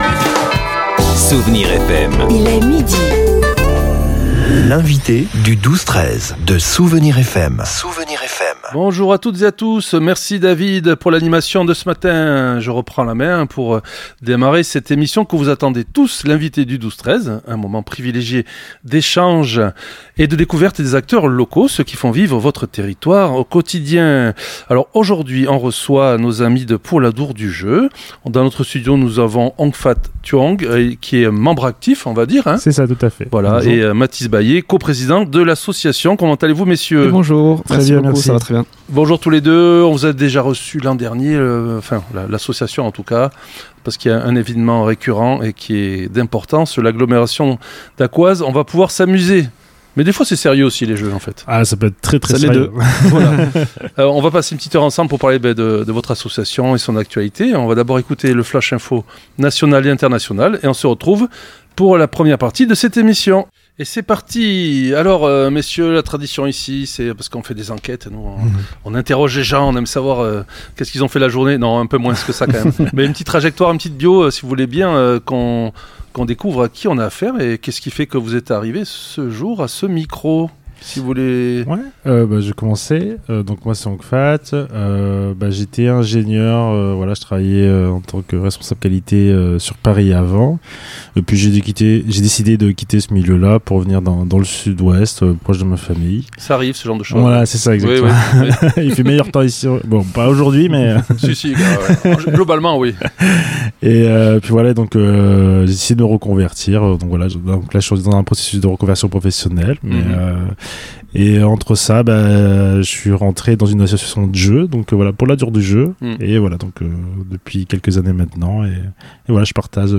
L'invite(e) du 12-13 de Dax recevait aujourd'hui